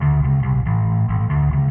描述：电贝司
Tag: 贝司